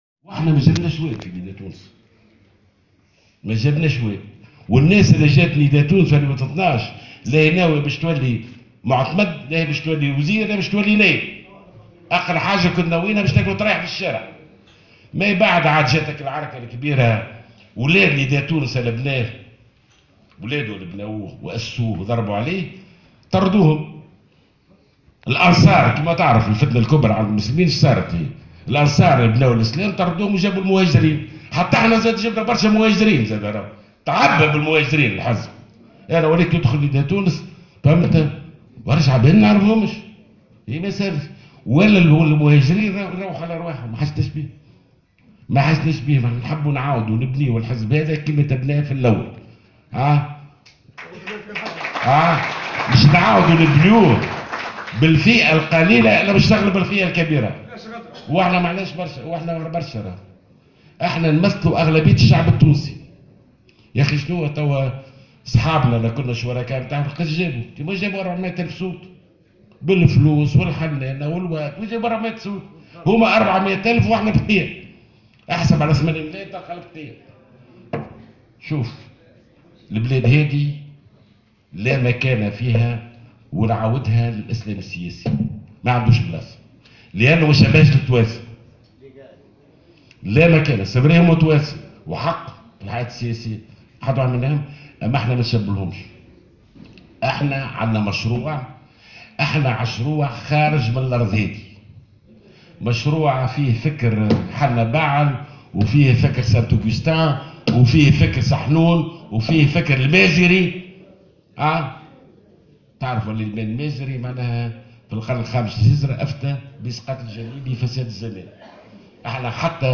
أشرف القيادي بحزب نداء تونس ناجي جلول اليوم السبت على حفل تنصيب المكتب المحلي لنداء تونس بالمنستير.